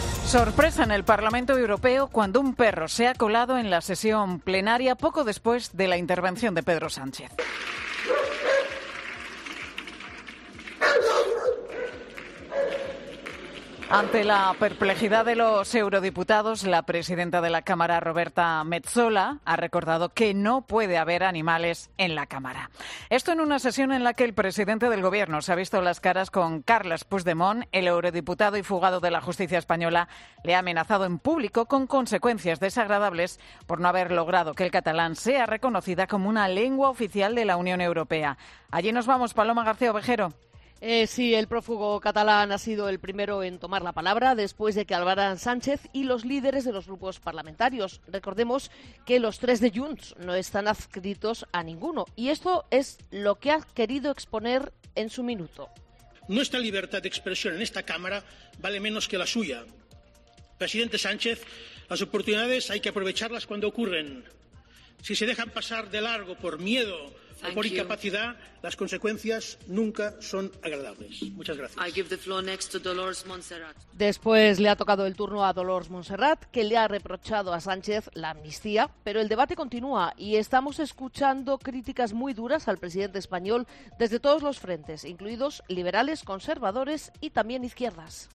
"Las oportunidades hay que aprovecharlas", ha dicho el expresidente prófugo de la Justicia durante la sesión en la Eurocámara
Puigdemont ha sido el primero en tomar la palabra después de que hablara Pedro Sánchez y los líderes de los grupos parlamentarios.